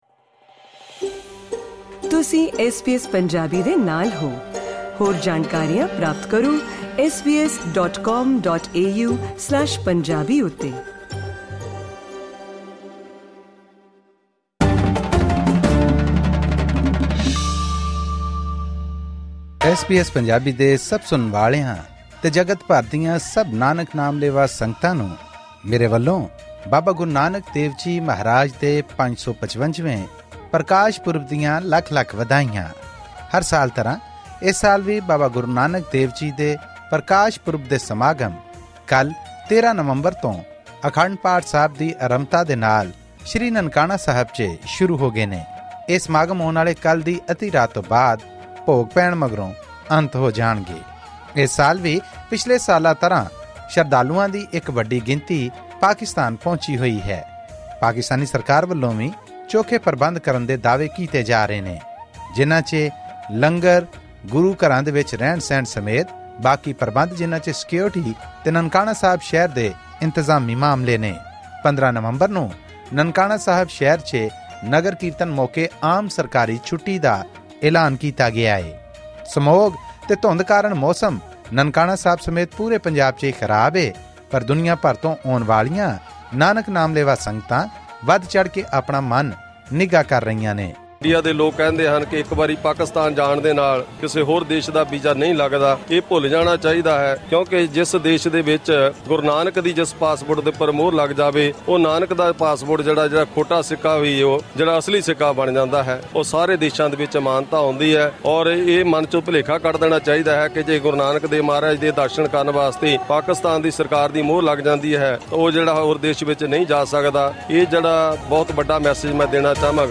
ਲਾਹੌਰ ਦੇ ਬਾਜ਼ਾਰਾਂ ਦੀ ਸਜਾਵਟ ਤੋਂ ਲੈ ਕੇ ਸ਼੍ਰੀ ਨਨਕਾਣਾ ਸਾਹਿਬ ਵਿਖੇ ਮੱਥਾ ਟੇਕਣ ਆਈਆਂ ਸੰਗਤਾਂ ਨਾਲ ਗੱਲਬਾਤ ਵੀ ਕੀਤੀ ਗਈ।